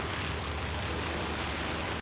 attack_loop.ogg